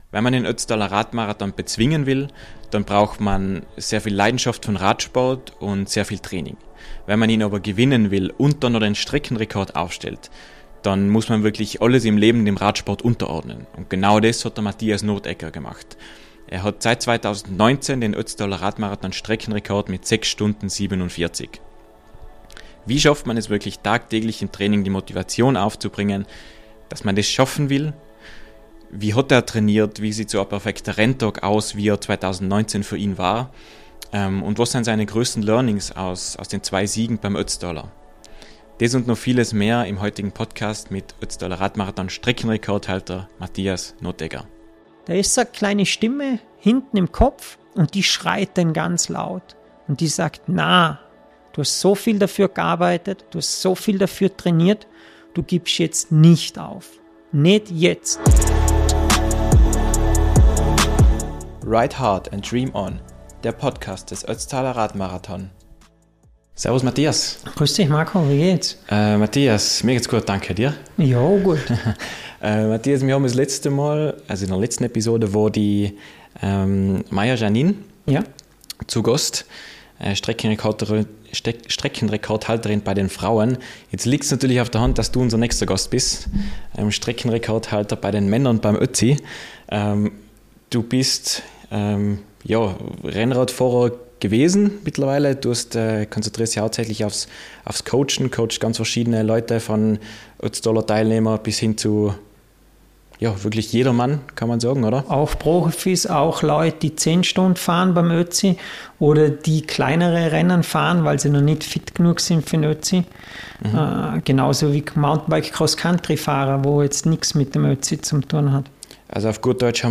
In unter 7 Stunden über 4 Alpenpässe? Ein spannendes Gespräch